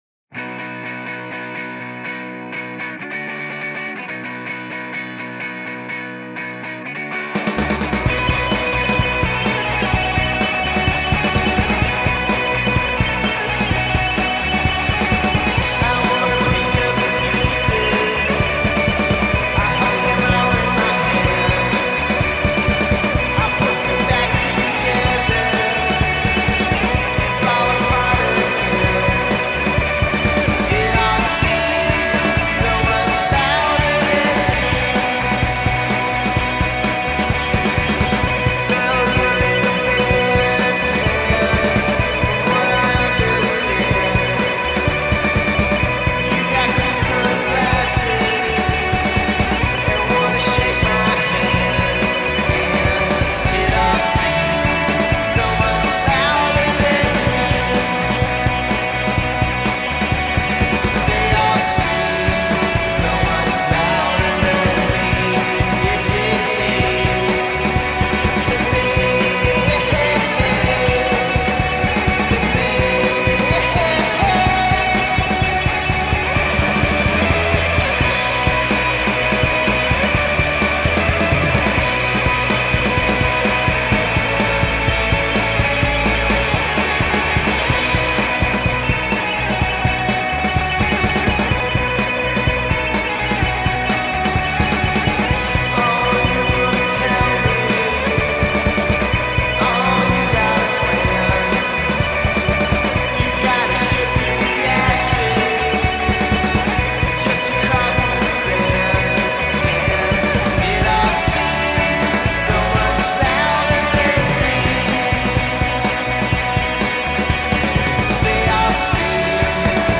From the opening fuzzed our chords
shoegaze pop